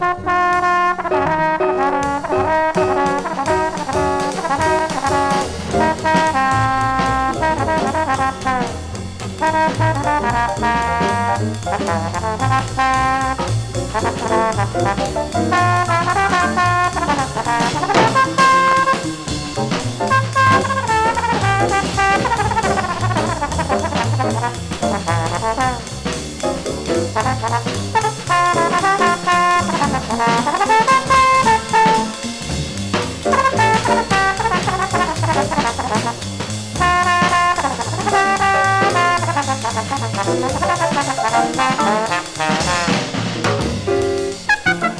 Jazz, Modal　USA　12inchレコード　33rpm　Stereo